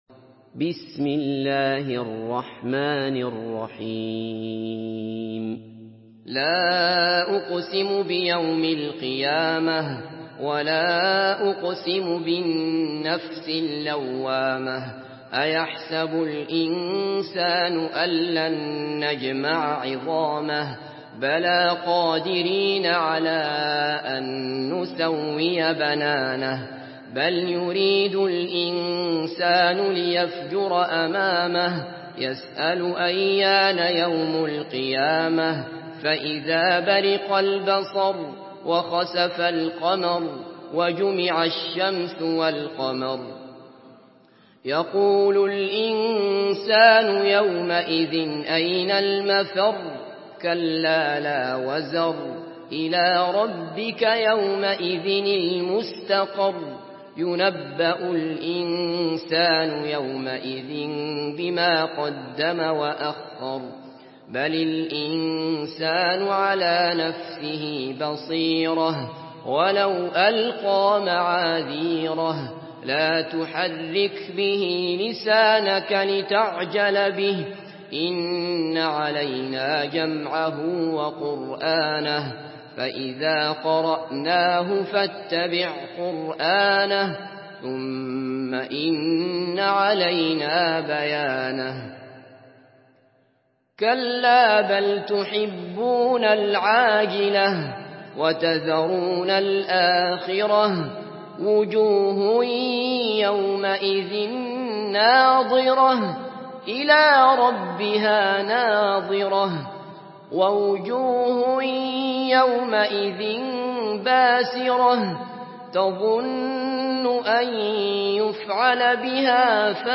Surah Kiyame MP3 in the Voice of Abdullah Basfar in Hafs Narration
Murattal Hafs An Asim